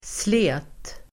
Uttal: [sle:t]